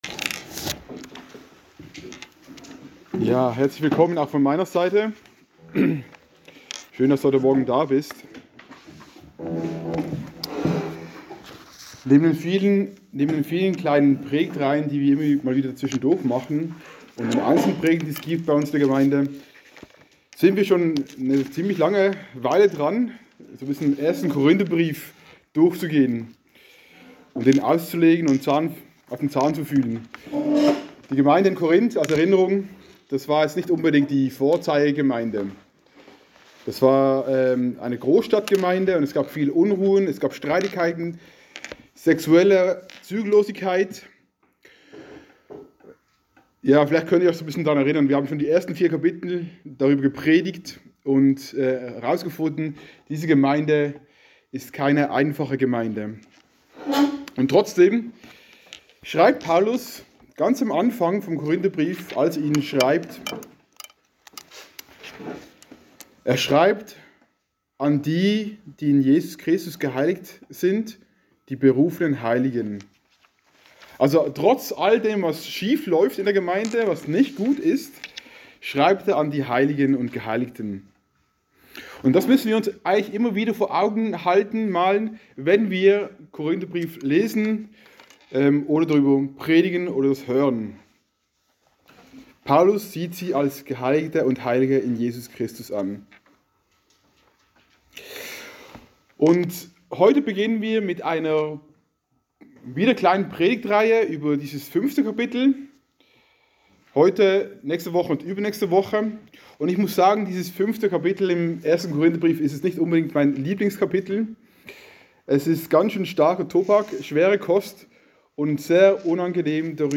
All Sermons Richtschnur zum richten 15 Januar, 2023 Series: Von Korinth nach Ricklingen Topic: Gemeindeausschluss , Gemeindeordnung , Richten , Sünde Book: 1.